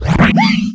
ogg / general / combat / creatures / alien / he / hurt2.ogg
hurt2.ogg